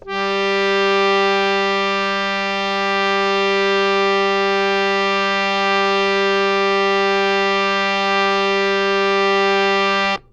harmonium
G3.wav